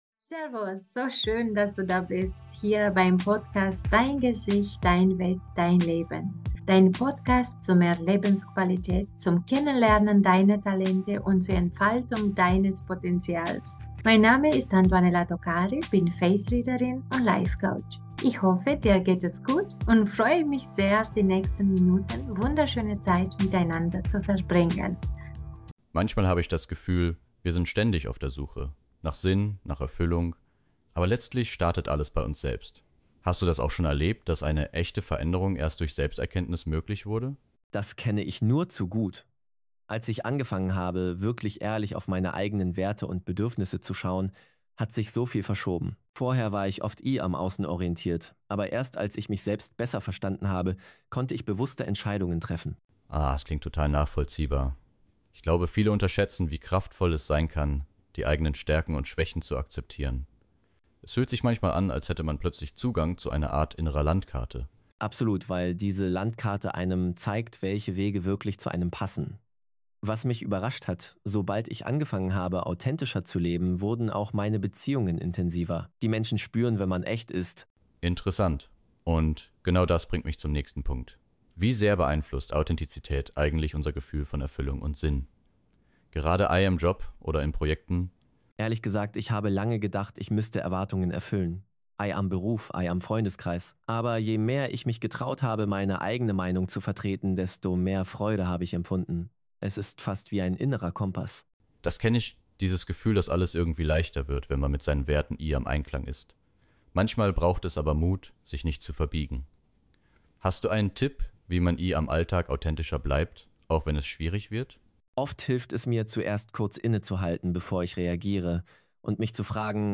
Hinweis: Dieses Interview wurde mit Unterstützung von KI